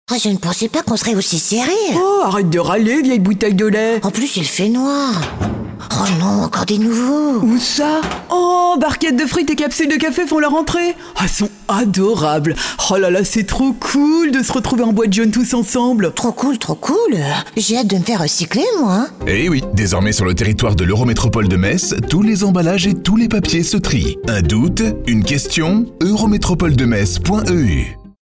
IMITATION ET TOON